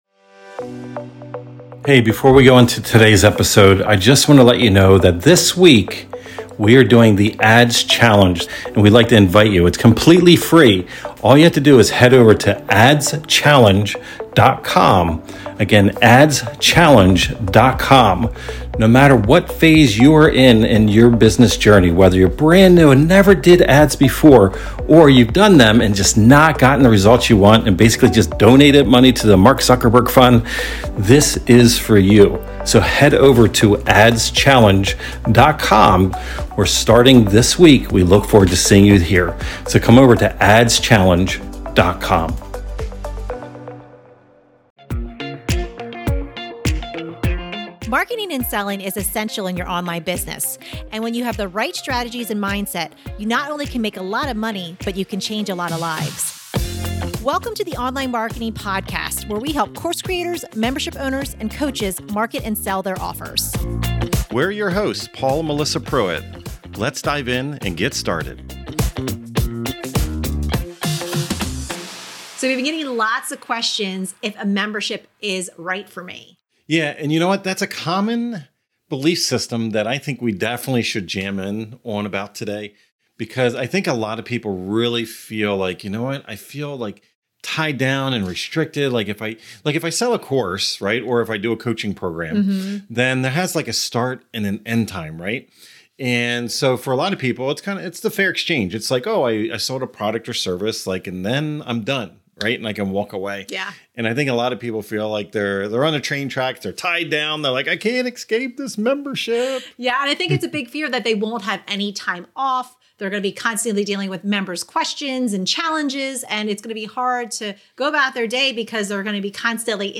Building Memberships That Last and Grow - Interview